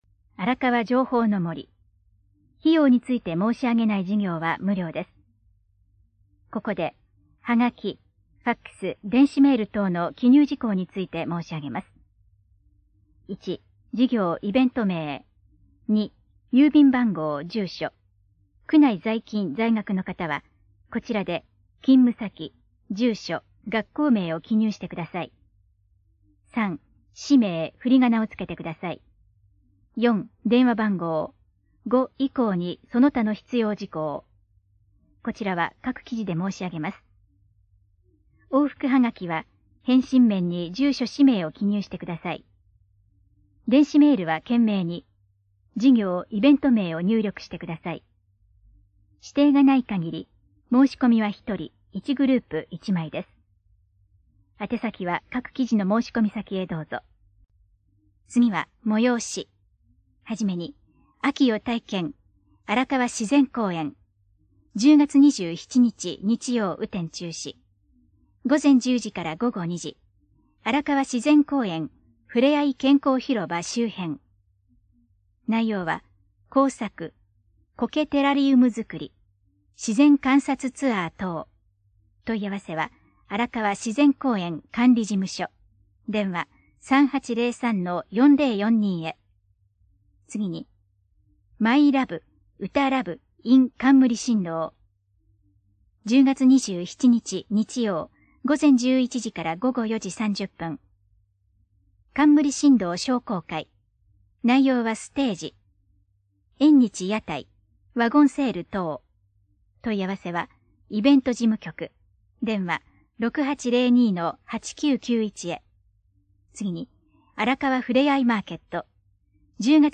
トップページ > 広報・報道・広聴 > 声のあらかわ区報 > 2019年 > 10月 > 2019年10月21日号